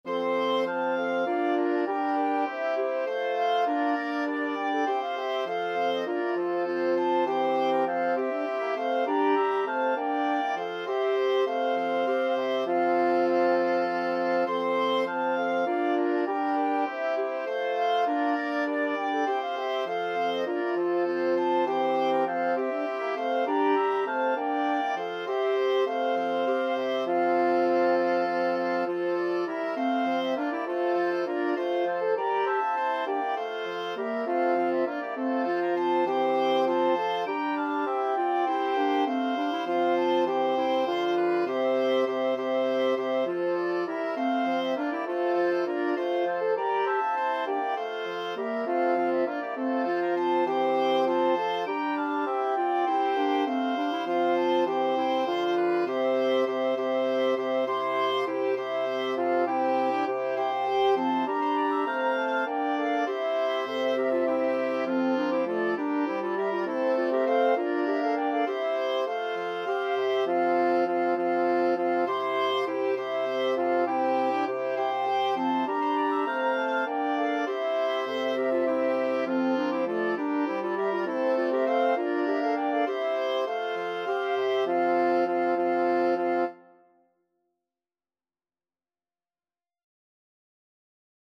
Free Sheet music for Wind Quintet
FluteOboeClarinetFrench HornBassoon
6/8 (View more 6/8 Music)
F major (Sounding Pitch) (View more F major Music for Wind Quintet )
Classical (View more Classical Wind Quintet Music)